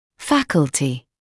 [‘fækltɪ][‘фэклти]факультет; способность; факультативность